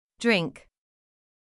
/ŋ/を正しく発音する際は、軽く口を開いて舌の付け根を上げ、「ンー」とハミングします。
音の響きを鼻で感じながら、鼻から息を抜くように発音します。
drink [drɪŋk]